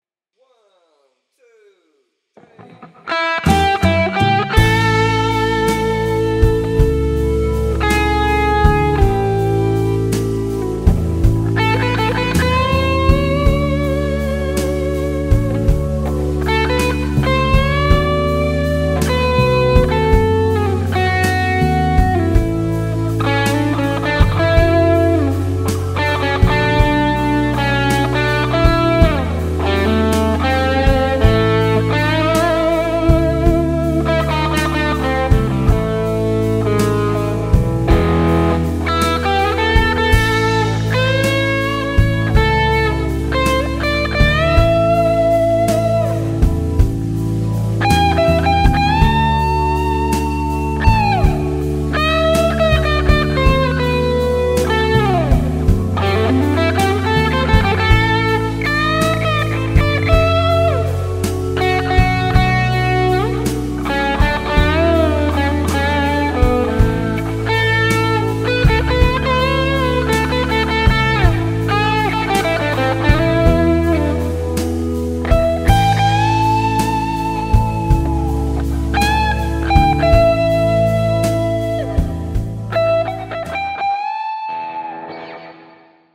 - soita soolosi annetun taustan päälle
- taustan tulee olla sointukierroltaan blues (I-IV-V)- sointuja ja tyyliä soveltaen.
Rauhallinen tunnelma. Taustaan hyvin istuvaa vapaa rytmitys, ja hitaiden venytysten kautta lisää rauhallisen mietiskelevää meininkiä kokonaisuuteen.
Malttia, pitkissä äänissä hyvää vibraa, tarinassa linjaa läpi vedon. Karkeahko soundi, parissa kohtaa vähän omituisia äänivalintoja/huteja.
Hyvä Juhla Mokka -blues. Soljuu mukavasti. 1p
tunnetta ja vibraa taustaan sopisvasti, kelpo soundi.